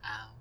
snd_enemy_hit.wav